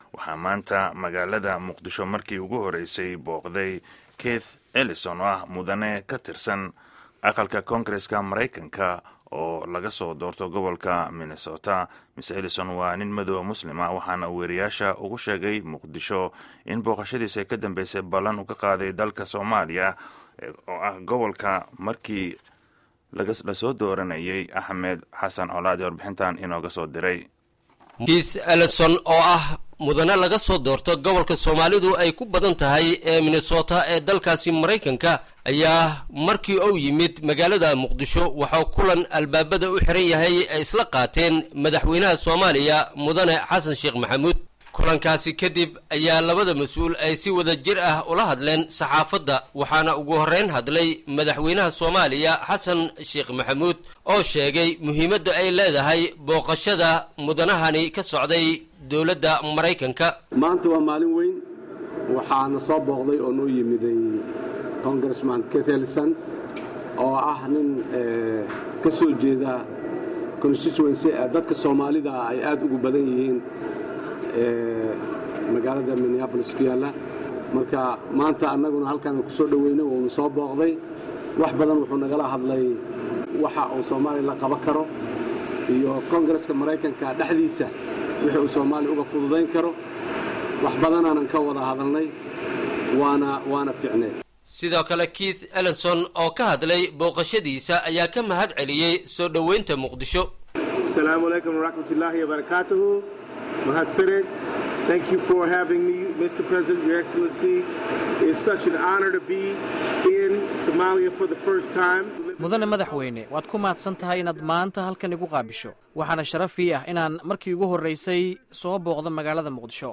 Warbixinta Booqashada Keith Ellison ee Muqdisho